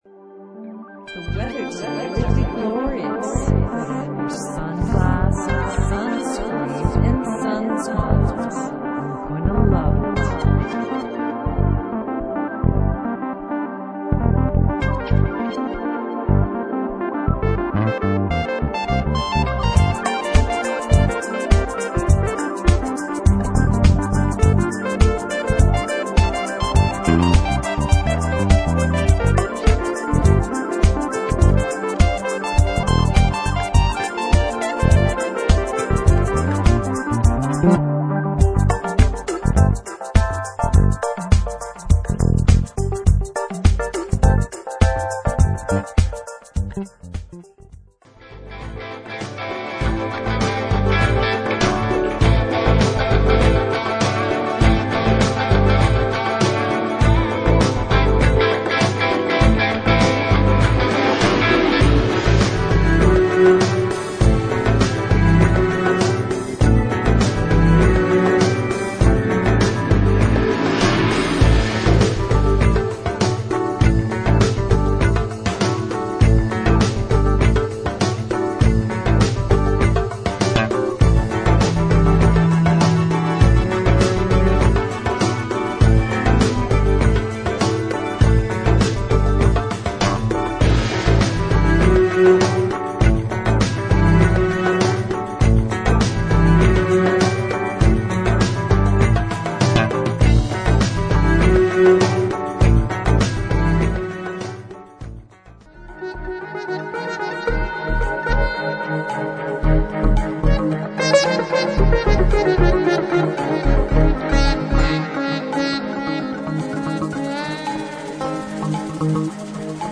3枚組スペシャル・コンピレーション・アルバム